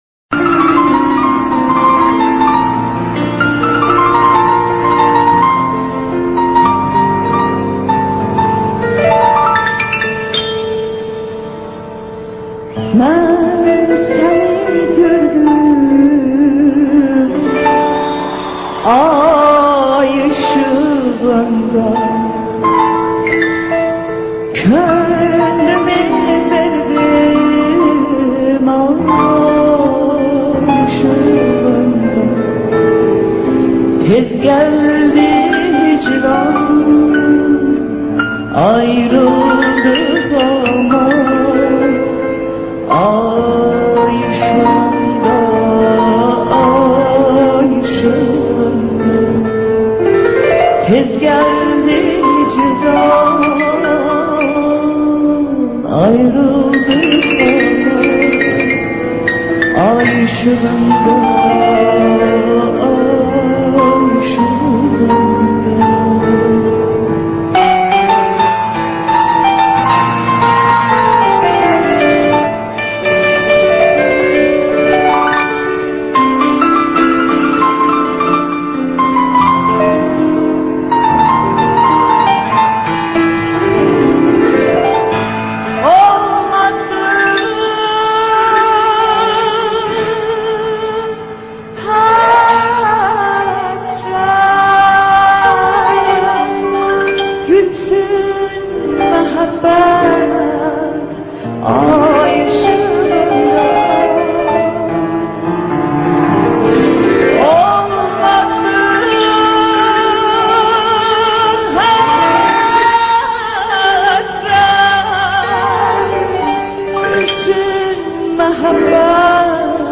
иранская певица и актриса азербайджанского происхождения.